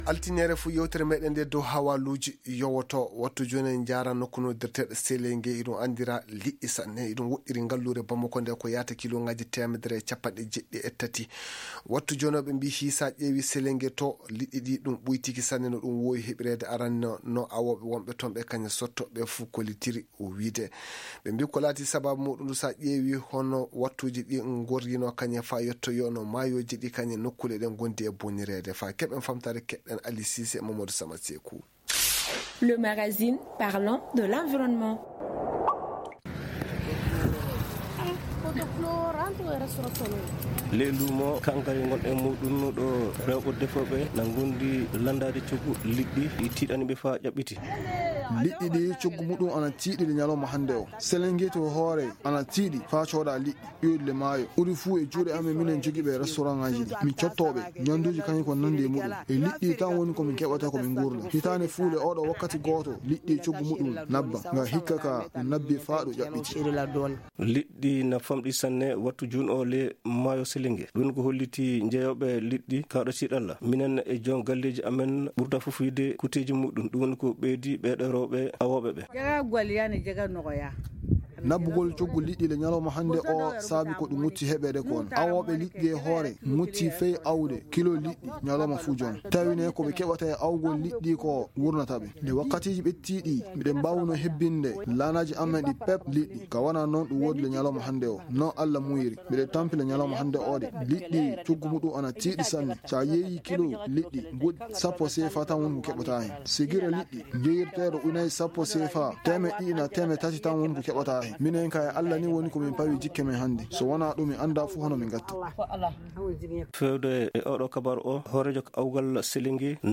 Magazine en peulh: Télécharger